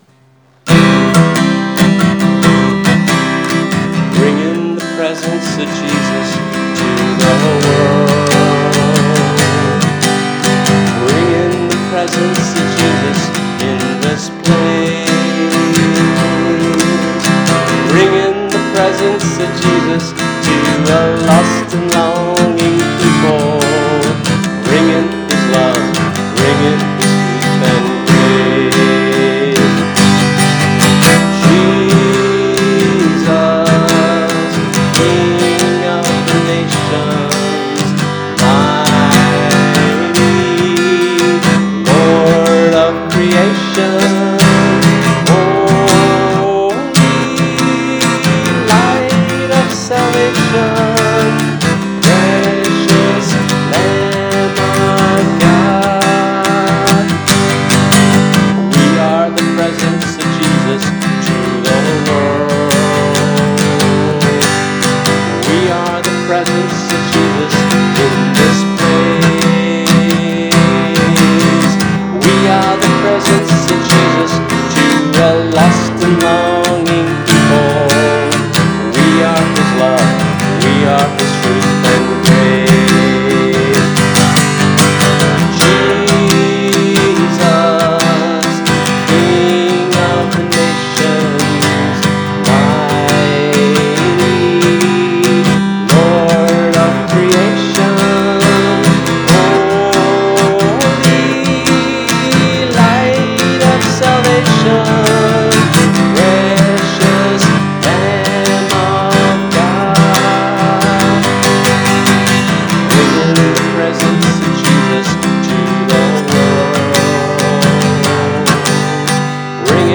(a light calypso, sung as a two part round)